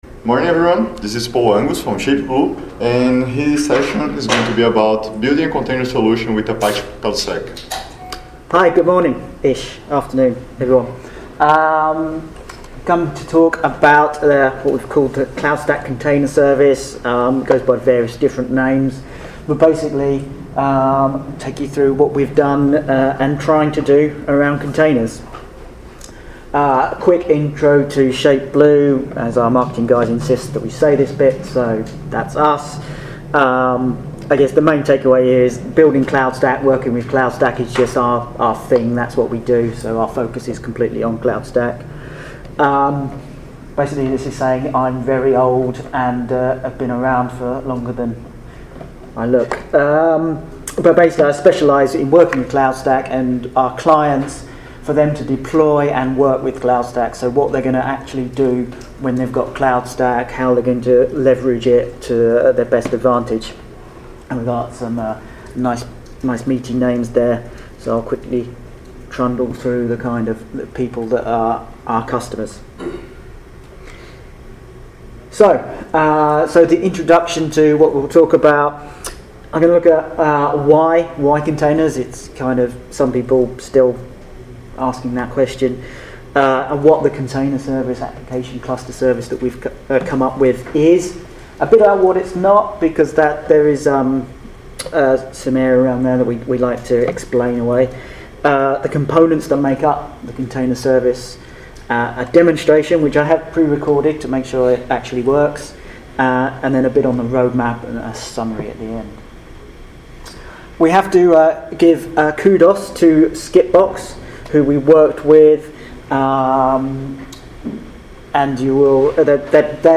ApacheCon Miami 2017
ShapeBlue Cloudstack Collaboration Conference